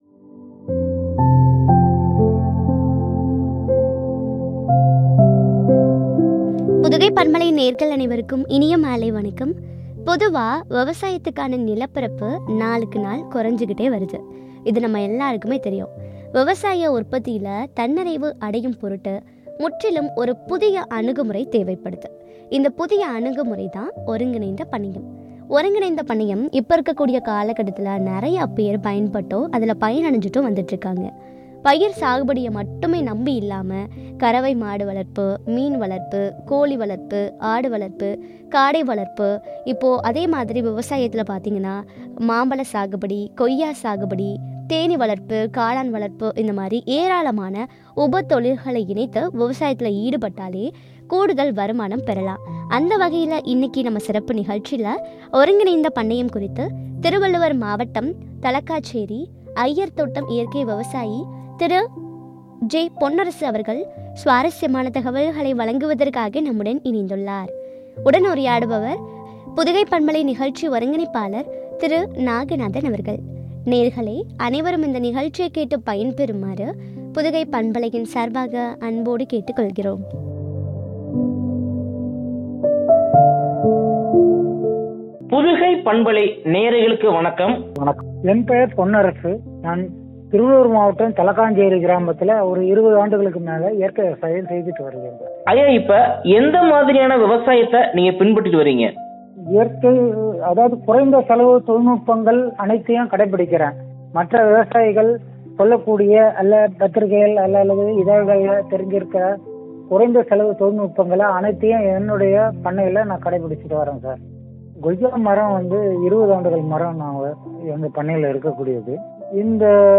“ஒருங்கிணைந்த பண்ணையம்” குறித்து வழங்கிய உரையாடல்.